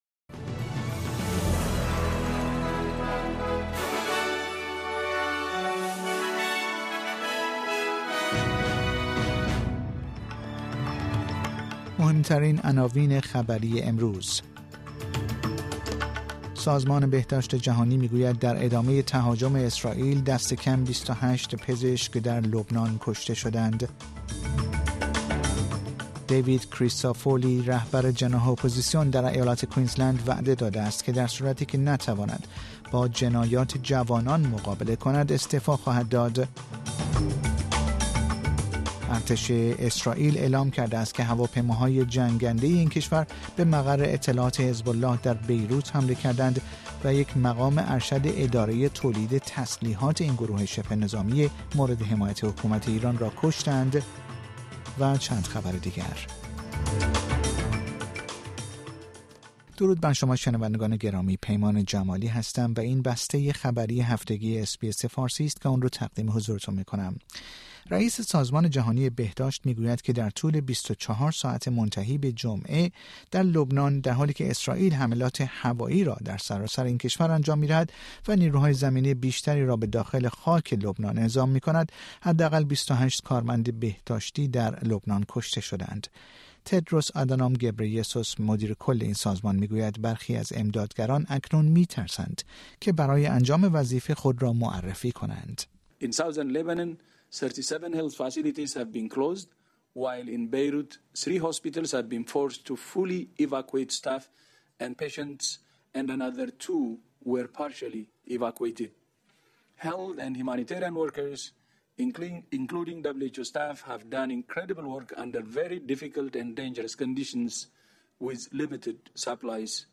در این پادکست خبری مهمترین اخبار استرالیا، جهان و ایران در یک هفته منتهی به شنبه ۵ اکتبر ۲۰۲۴ ارائه شده است.